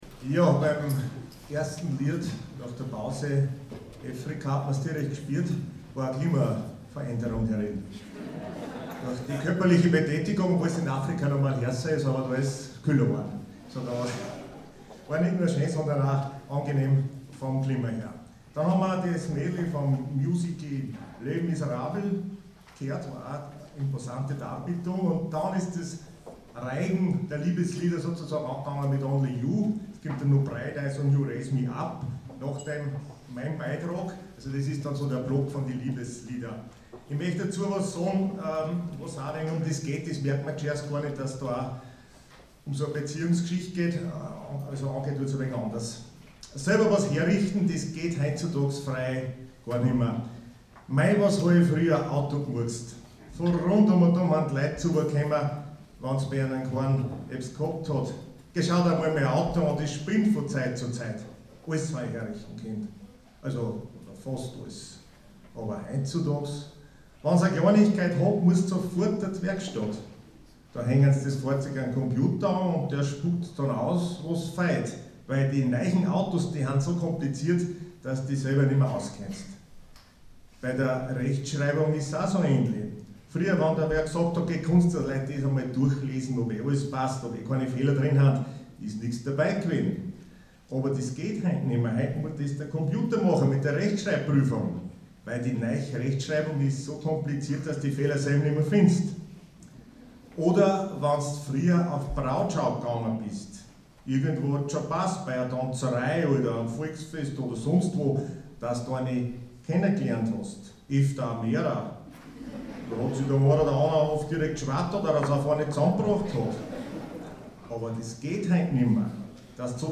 24.06.2017 - Konzert - SOMMERCOCKTAIL